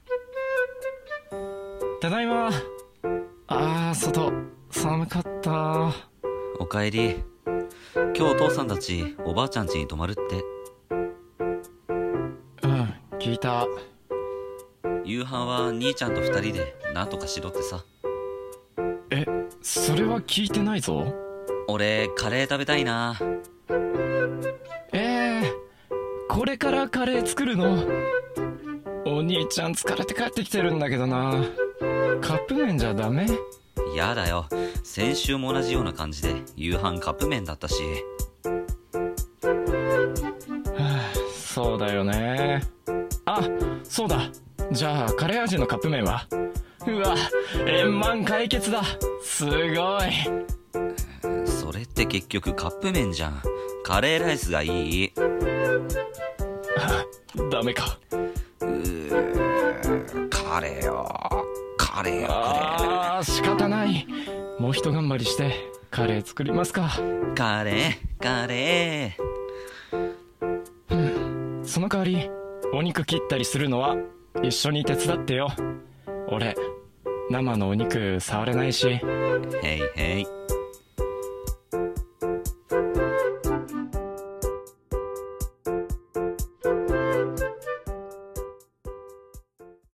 【声劇台本】カレーがいい 【コラボ募集】